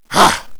warrior_attack2.wav